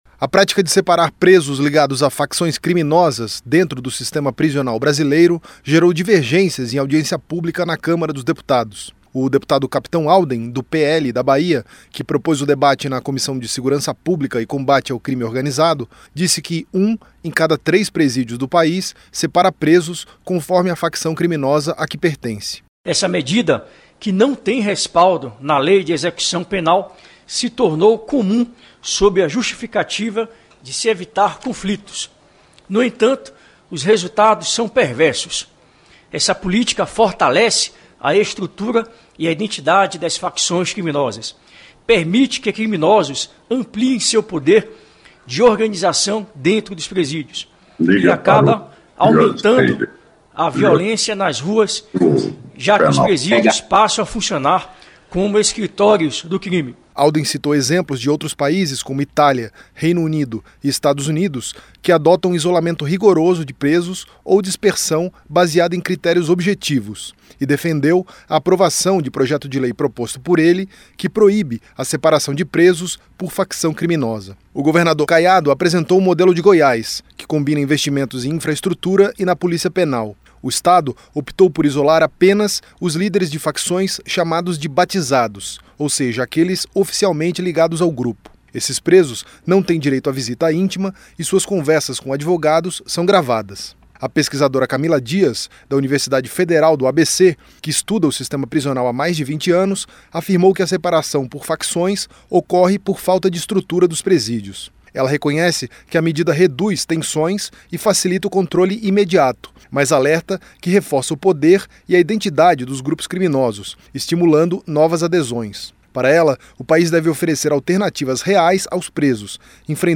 Políticos e pesquisadores divergem sobre separar presos ligados a facções em presídios - Radioagência